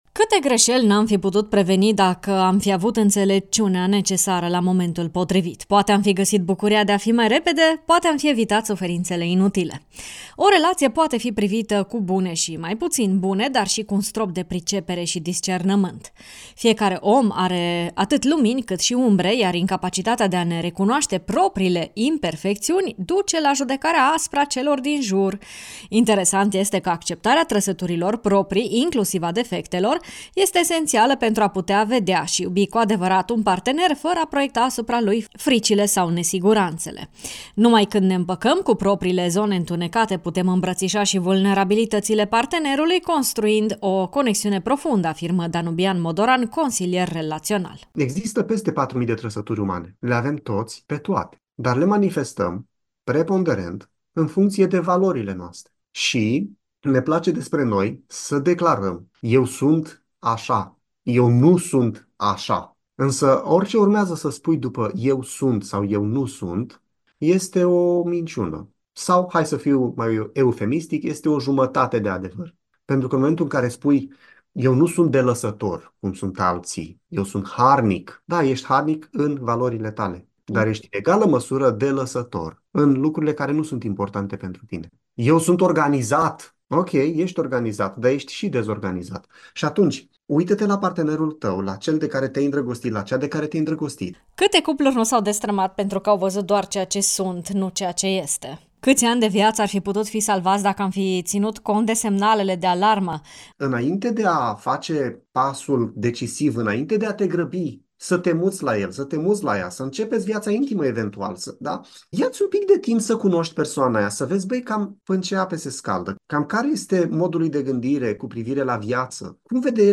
consilier relational: